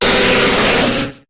Cri de Nostenfer dans Pokémon HOME.